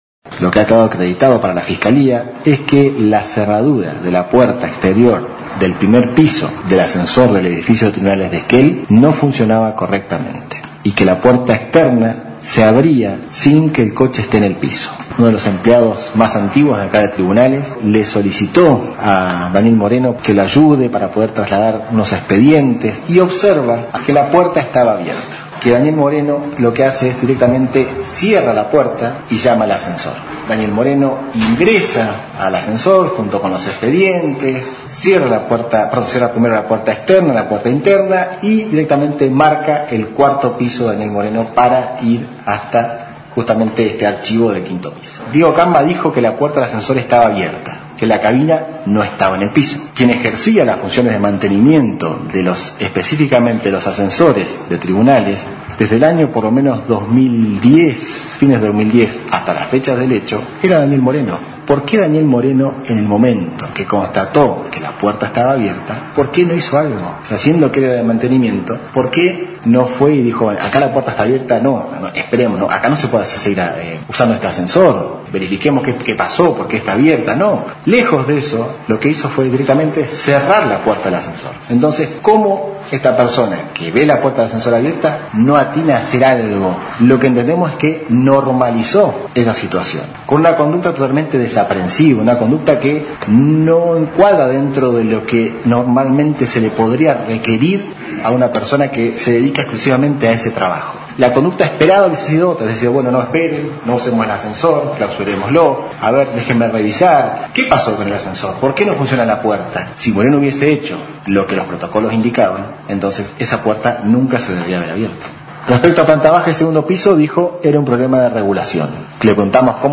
En la sala de audiencias N° 2 de los tribunales de Esquel, se realizó la audiencia de alegatos presidida por el juez Penal Martín O´Connor
Alegato Fiscal.